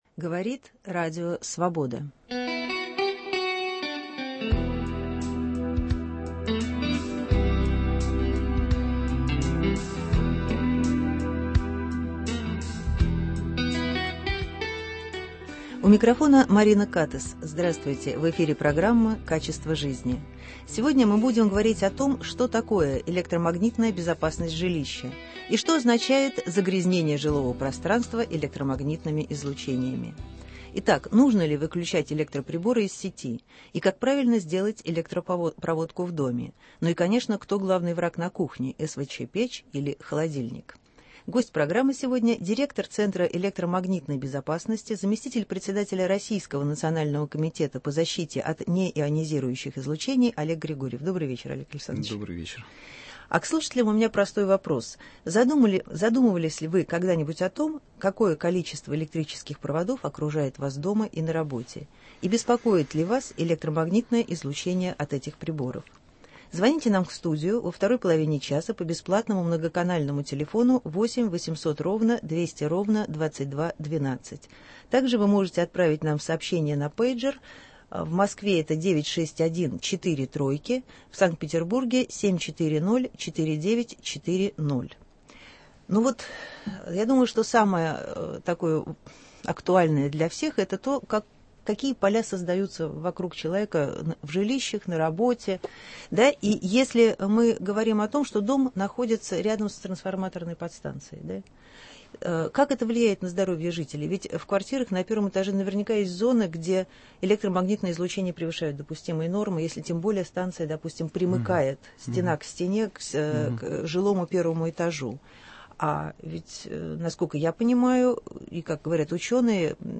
Как правильно сделать электропроводку в доме и кто главный враг на кухне – СВЧ печь или холодильник? Гость студии